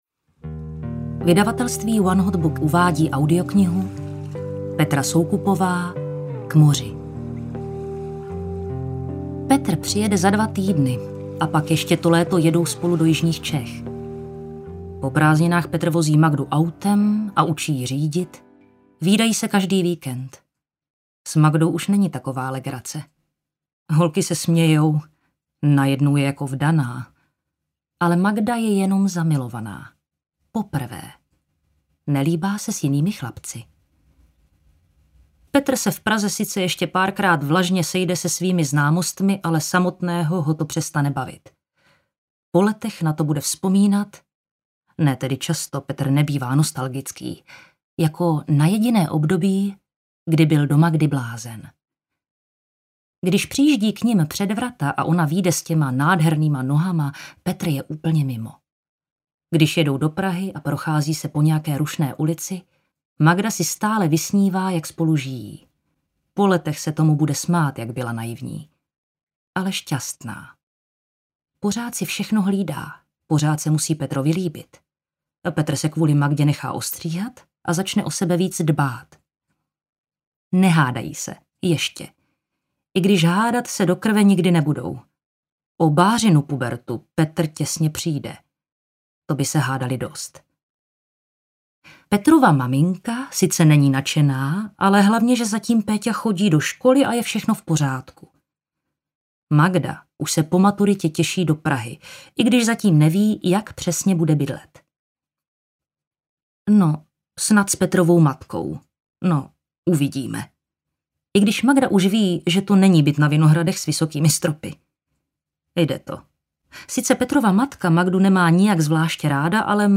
K moři audiokniha
Ukázka z knihy